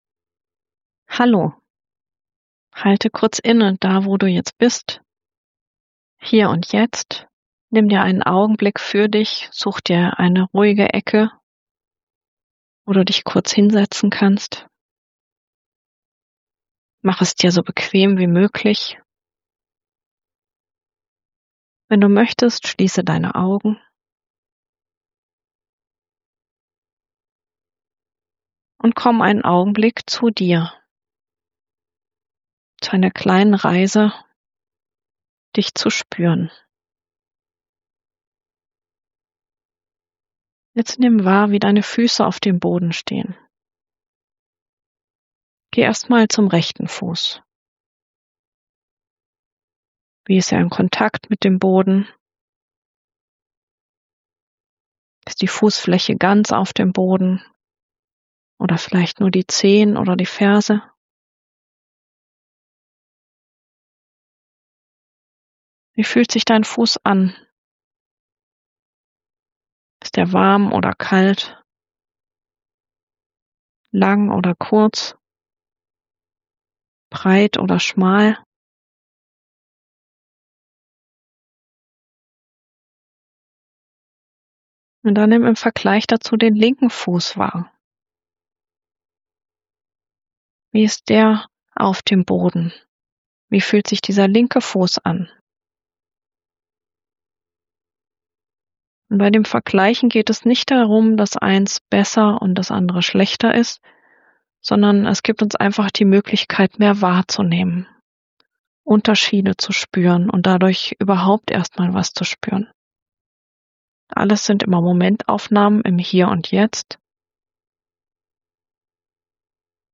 Erlaube Dir, Dich einfach zu spüren - ohne zu bewerten oder etwas tun zu müssen. Die Anleitung ist im Sitzen, Du kannst sie aber auch im Liegen oder Stehen machen.